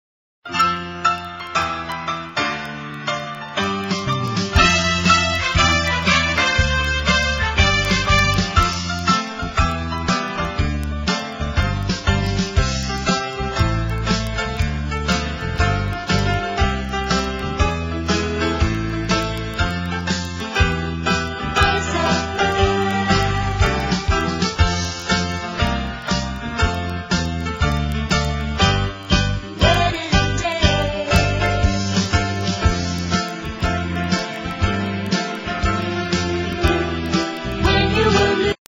NOTE: Background Tracks 7 Thru 12